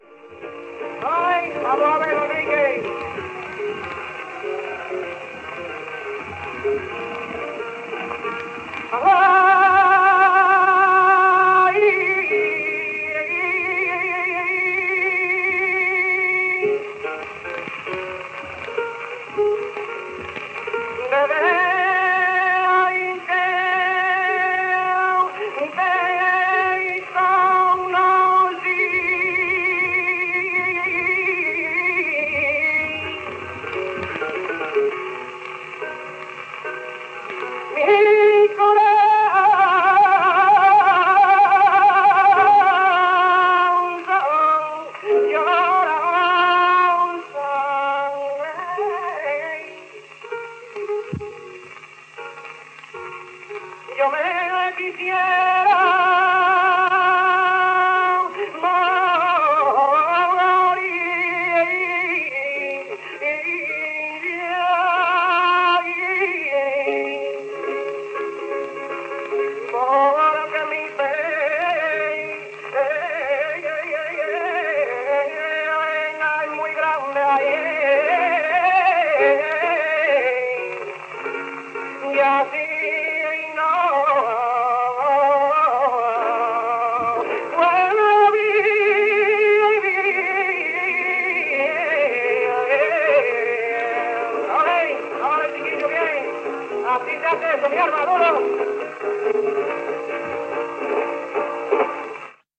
guitare